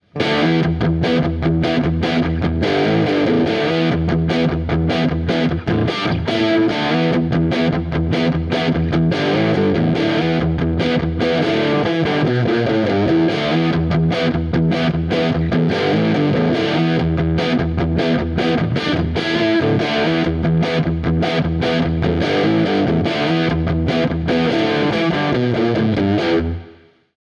Die beiden aktiven Roswell Humbucker können im Test mit verzerrten Sounds durchaus überzeugen.
Bei mittleren bis starken Zerrgraden behalten die Tonabnehmer allgemein ihre Definition und Tightness im Low End. Bei Riffs mit sehr starker Verzerrung gehen diese beiden Eigenschaften allerdings ein Stück weit verloren.
Harley Benton EX-84 Modern BK Test: Klangbeispiele
Heavy Riff